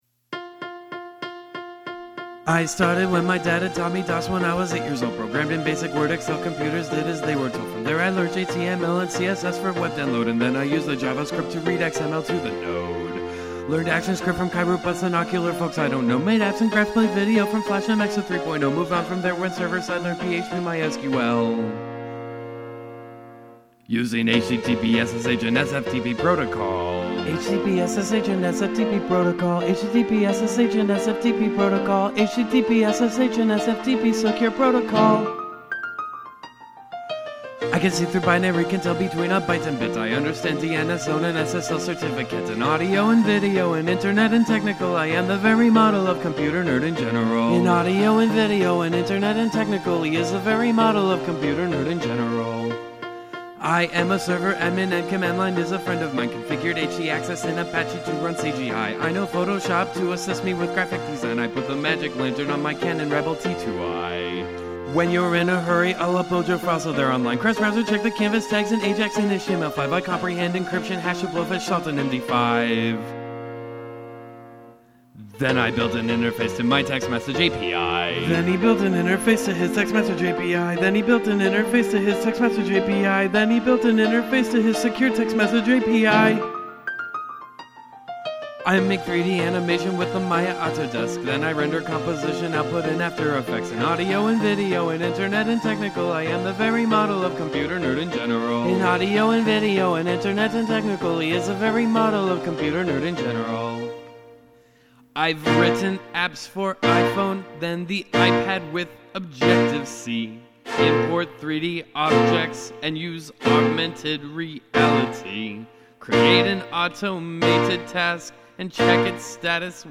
UPDATE:  I have put the vocals in including the background vocals so you'll have an idea what to do:
I split the difference and recorded the fast part at 190 beats per minute and the slow part just 10 beats per minute slower.